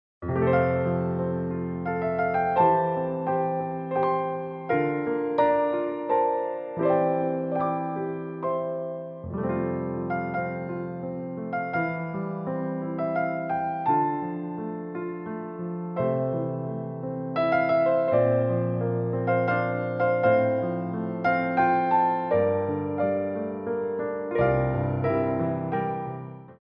Theme Songs from Musicals for Ballet Class
Piano Arrangements
Pliés 1
3/4 (16x8)